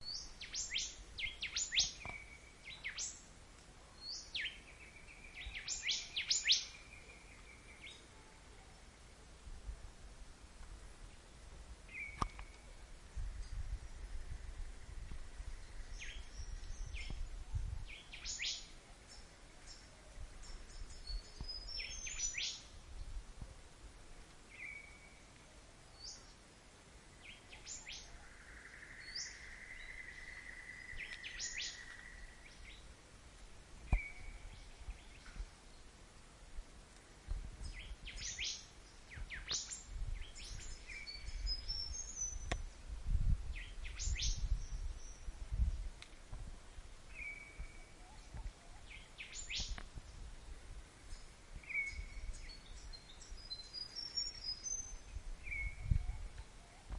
森林环境实地录音
描述：森林环境实地录音。春日森林鸟在唱歌。
标签： 森林 树木 唱歌 鸟类 环境 春日 实地录音 自然
声道立体声